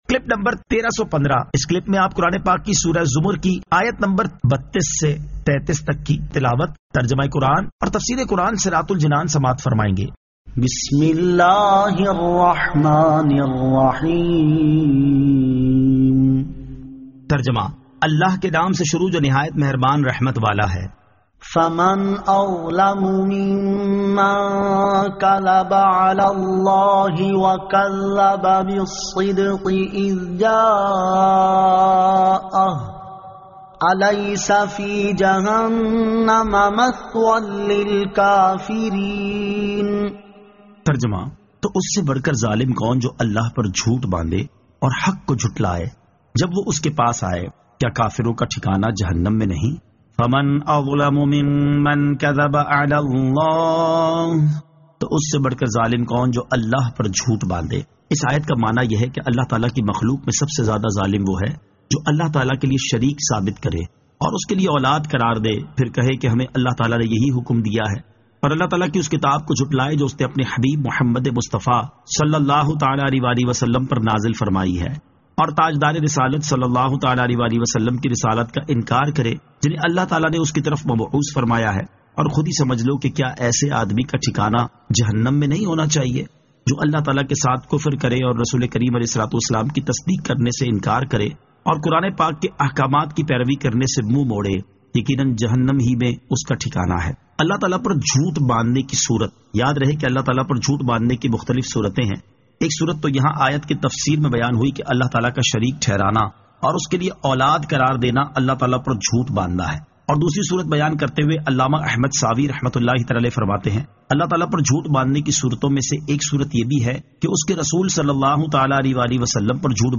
Surah Az-Zamar 32 To 33 Tilawat , Tarjama , Tafseer